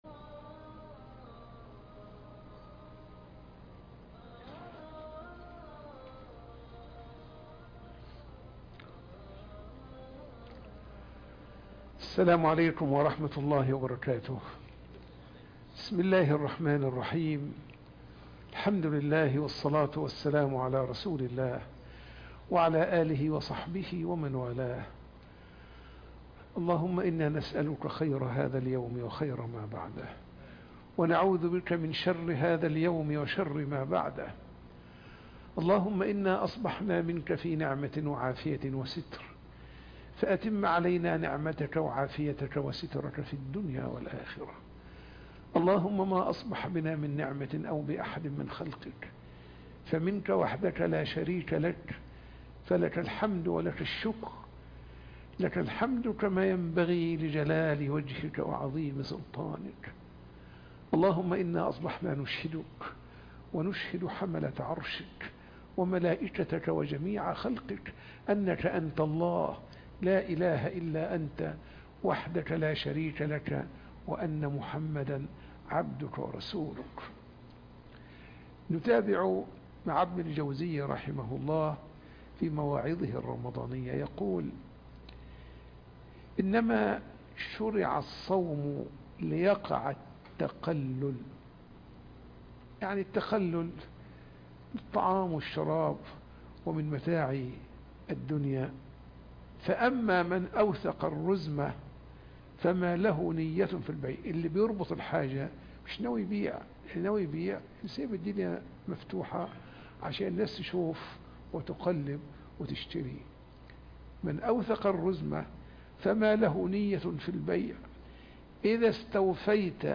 مواعظ رمضانية لابن الجوزي -رحمه الله- 3 (درس بعد الفجر